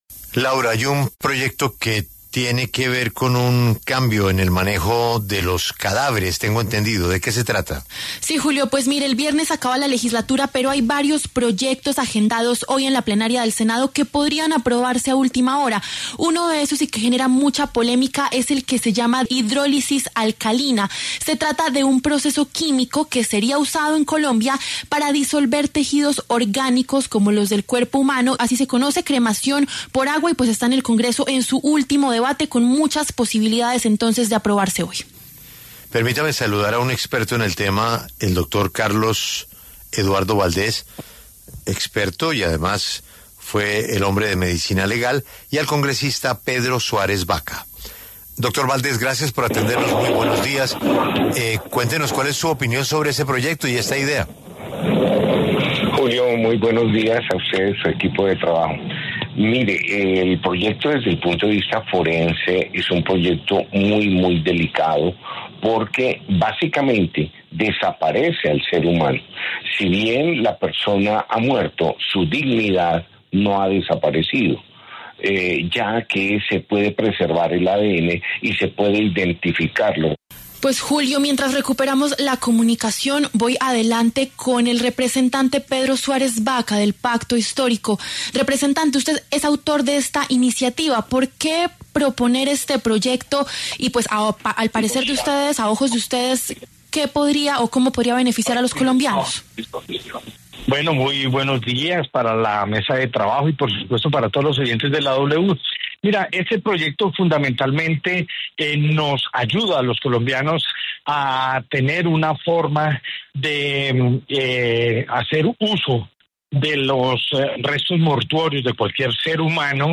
El exdirector de Medicina Legal, Carlos Valdés, y el representante Pedro Suárez, del Pacto Histórico, pasaron por los micrófonos de La W.